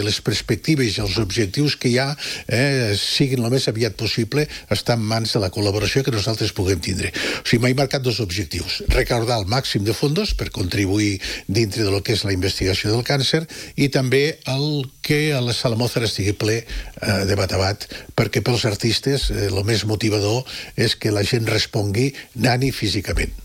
ha explicat en una entrevista a RCT que l’objectiu és omplir la sala i recaptar el màxim de fons possibles.